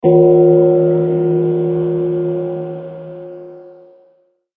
環境音 （38件）
鐘.mp3